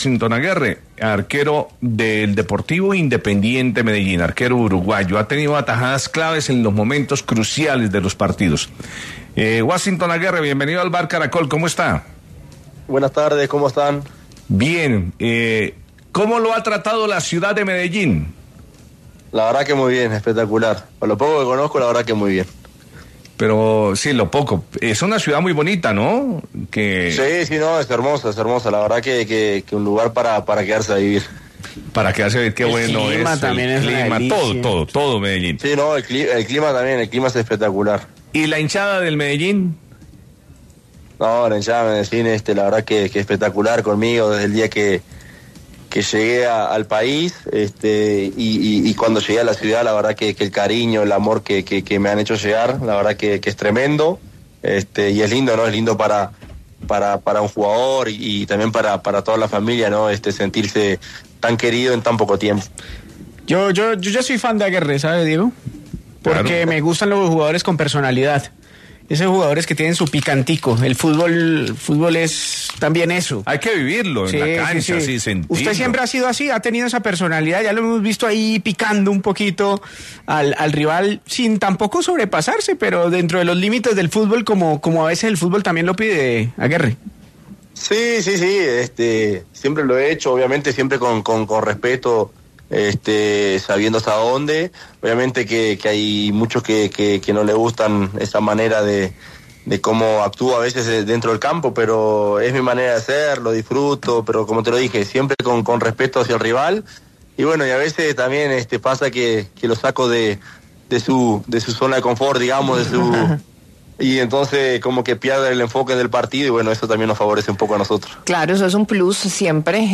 El arquero uruguayo en diálogo con el Vbar de Caracol Radio opinó sobre la hinchada de Independiente Medellín. Además, también se expresó sobre esa personalidad retadora que suele tener durante los partidos.